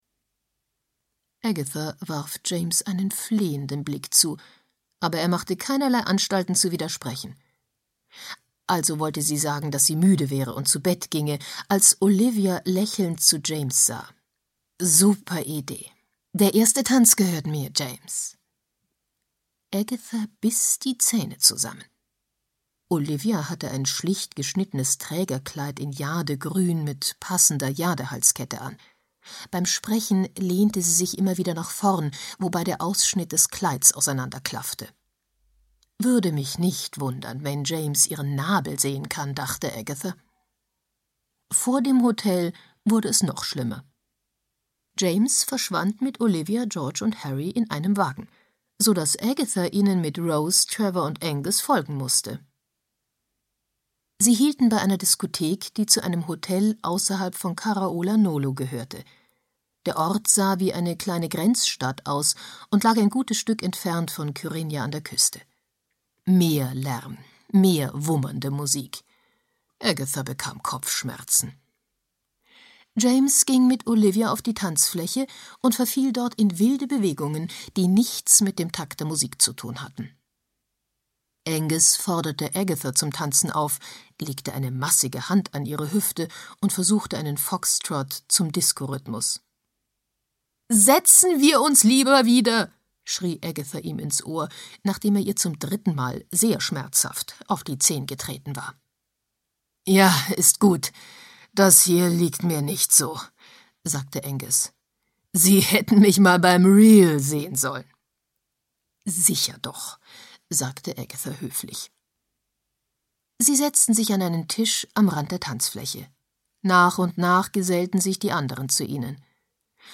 Hörbuch Agatha Raisin und die tote Urlauberin von M. C. Beaton.
Ukázka z knihy